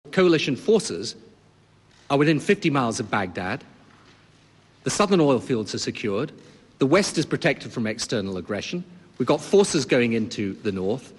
Wojna potrwa tak długo, ile potrzeba do zwycięstwa - powiedział prezydent USA George W.Bush na wspólnej konferencji prasowej z brytyjskim premierem Tonym Blairem po rozmowach w Camp David.
Wtórował mu Blair (88Kb)